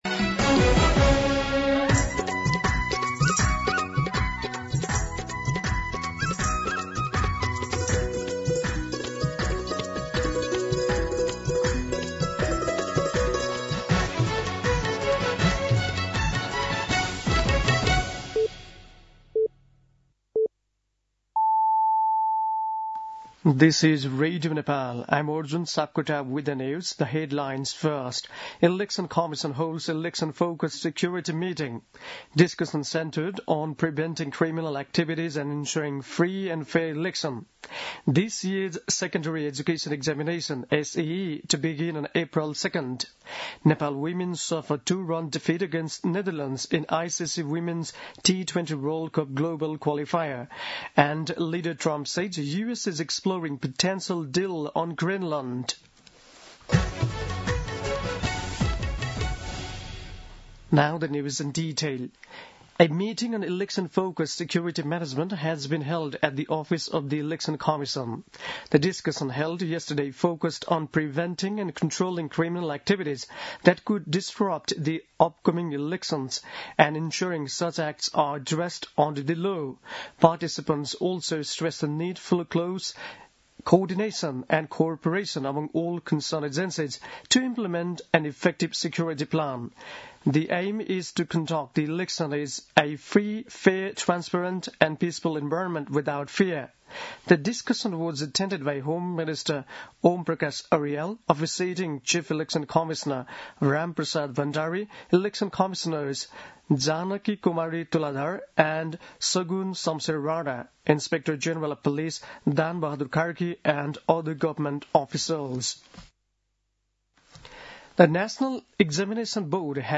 दिउँसो २ बजेको अङ्ग्रेजी समाचार : ८ माघ , २०८२